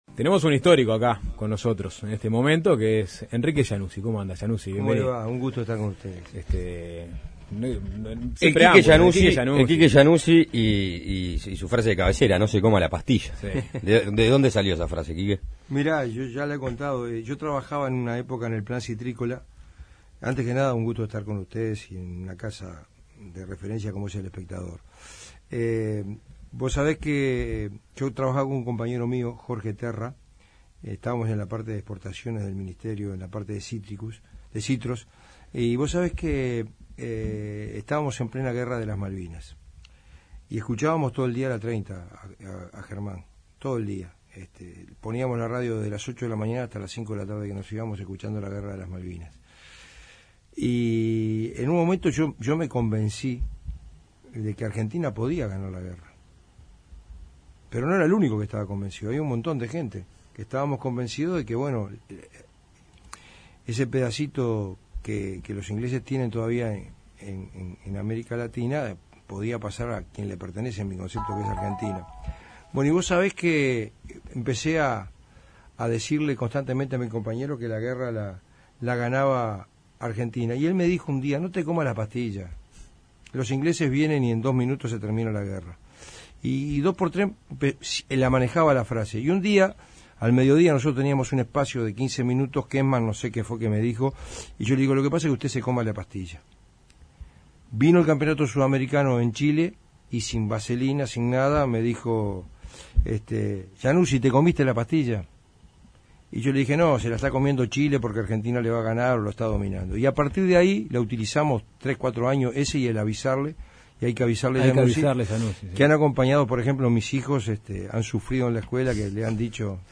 Uno de los periodistas deportivos más reconocidos en el medio uruguayo visitó Suena Tremendo. El comentarista habló sobre Paco Casal y Tenfield, política partidaria y su eventual retirada del periodismo deportivo.